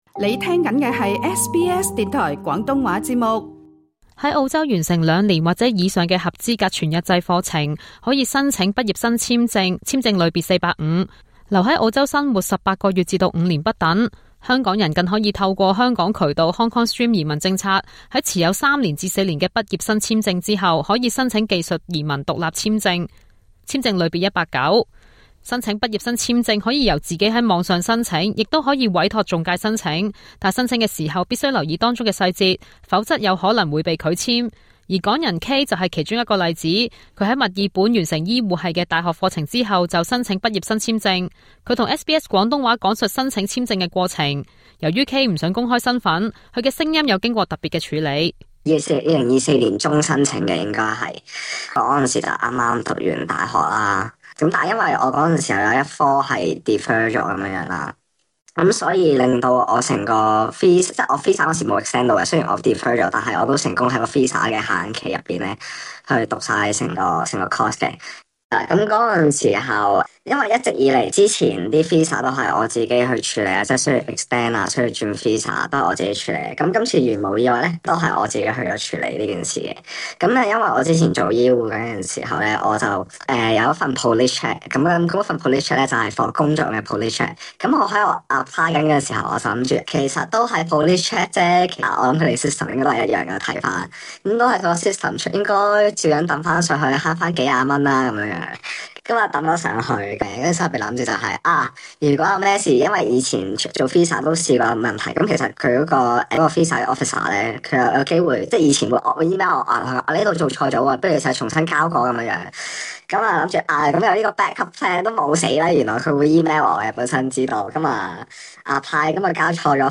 詳細內容請收聽錄音訪問